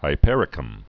(hī-pĕrĭ-kəm)